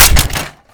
weap_hvy_gndrop_3.wav